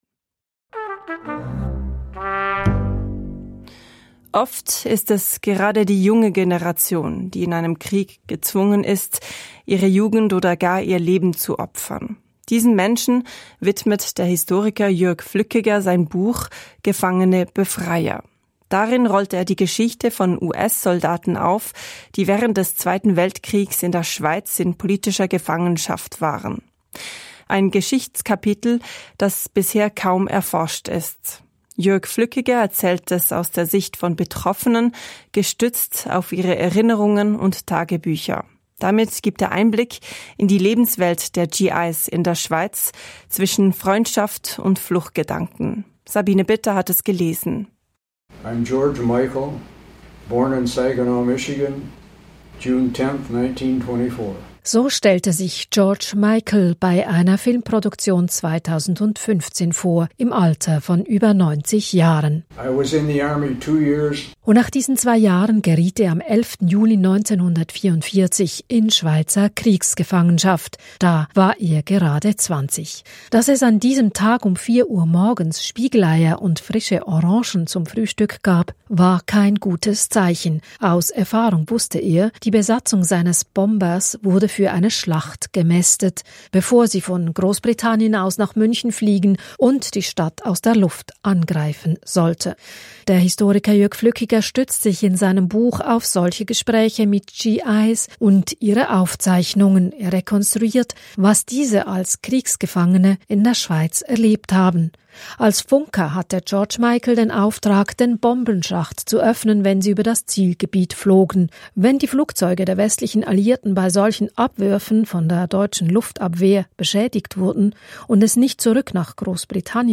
Radiobeitrag SRF Kultur Kompakt vom 15.12.2025